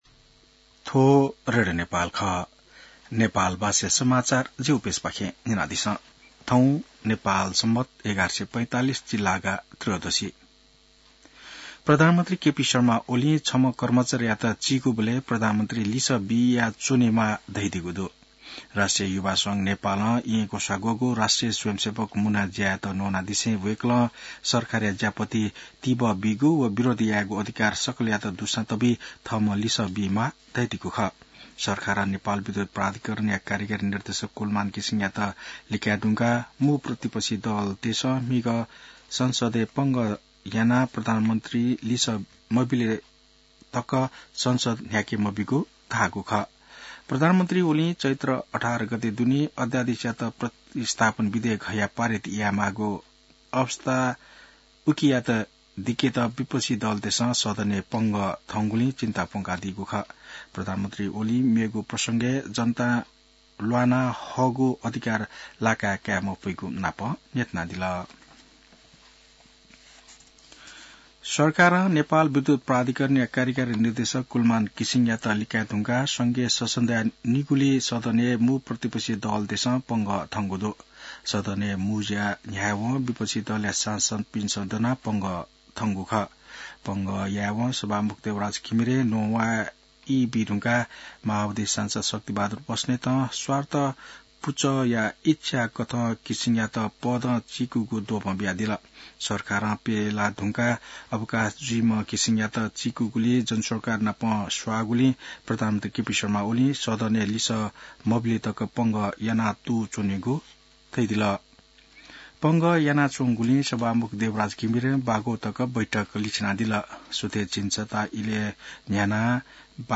नेपाल भाषामा समाचार : १४ चैत , २०८१